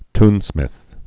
(tnsmĭth, tyn-)